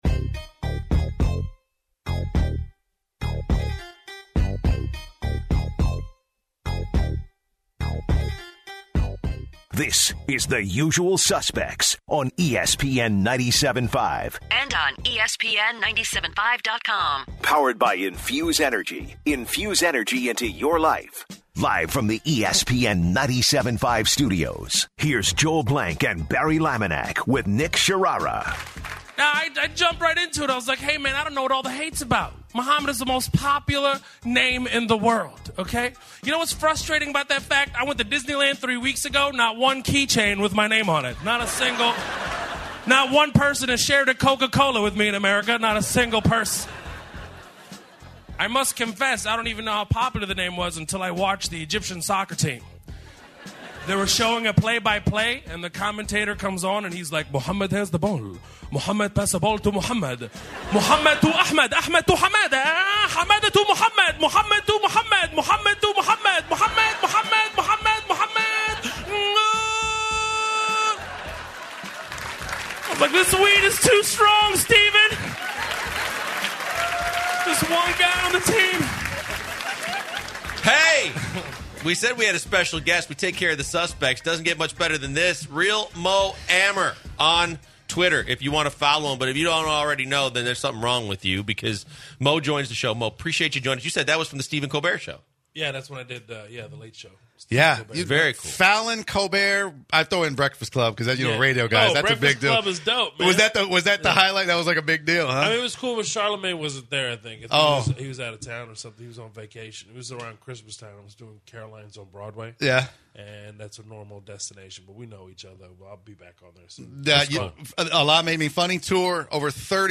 Headliner Embed Embed code See more options Share Facebook X Subscribe The guys kick off the final hour of the show with comedian Mo Amer in studio to talk sports fandom, opening for Dave Chappelle, the Houston comedy scene and his upcoming Netflix special “Vagabond”. Then the guys react live to the Astros-Rays game and discuss whether you deserve an RBI if a run scores on a walk and more odd baseball rules.